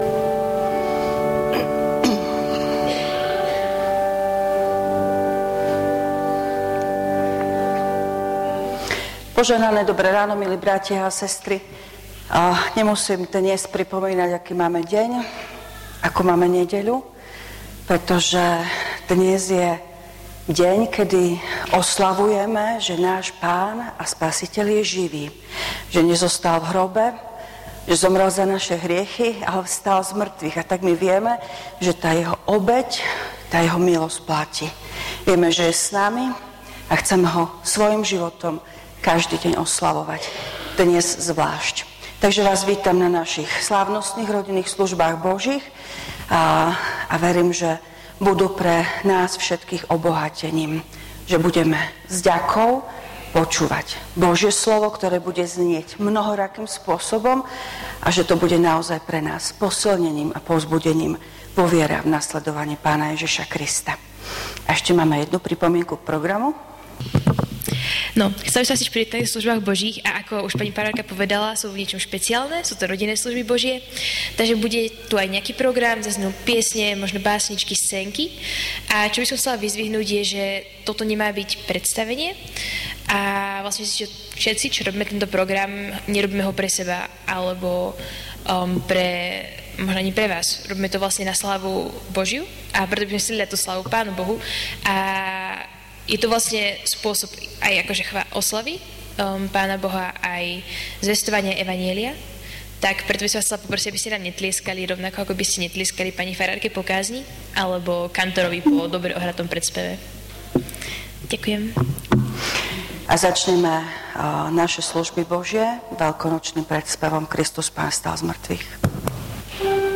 Služby Božie – 1. slávnosť veľkonočná
V nasledovnom článku si môžete vypočuť zvukový záznam zo služieb Božích – 1. slávnosť veľkonočná.